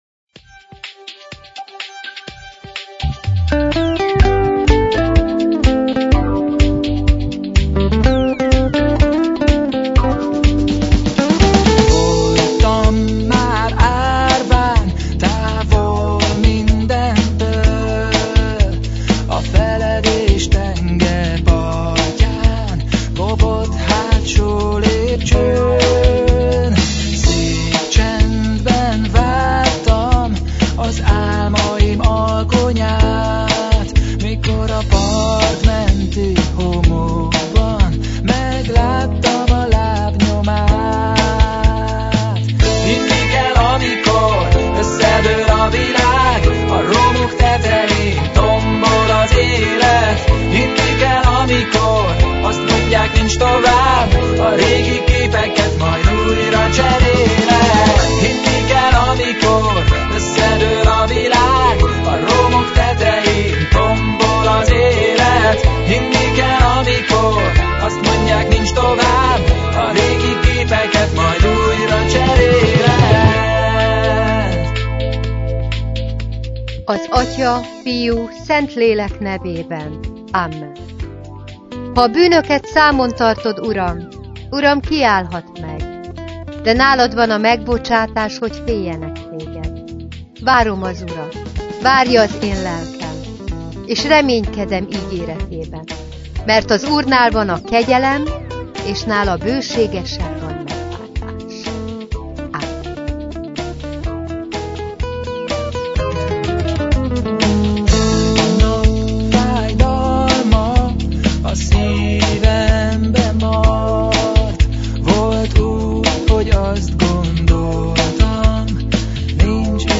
Igét hirdet
evangélikus lelkipásztor.